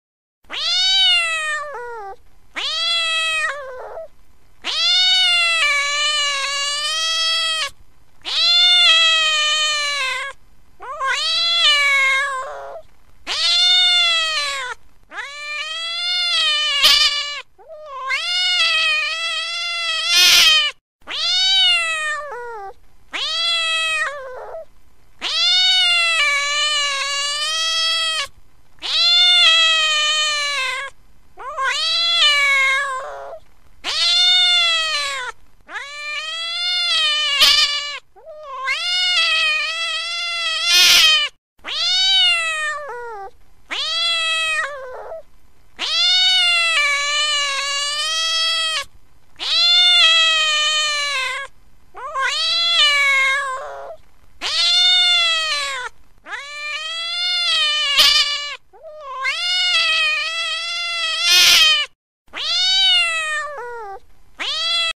جلوه های صوتی
دانلود صدای گربه وحشی از ساعد نیوز با لینک مستقیم و کیفیت بالا
برچسب: دانلود آهنگ های افکت صوتی انسان و موجودات زنده دانلود آلبوم صدای گربه عصبانی از افکت صوتی انسان و موجودات زنده